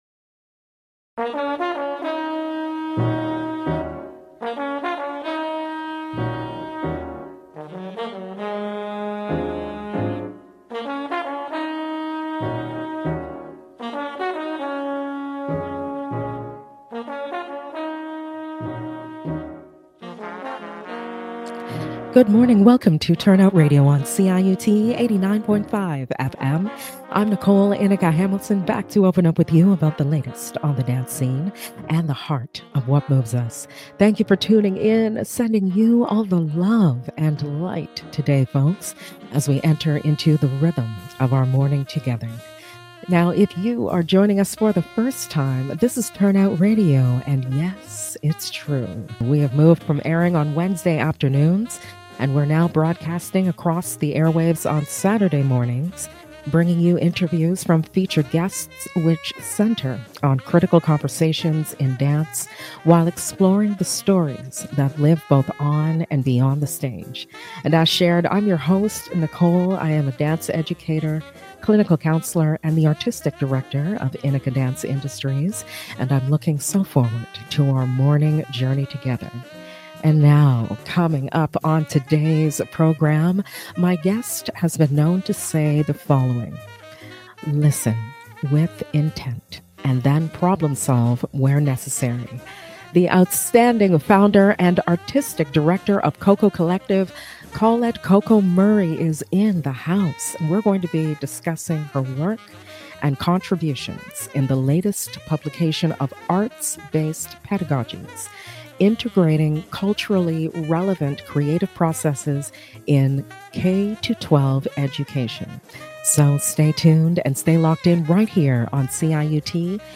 L ive on CIUT 89.5 FM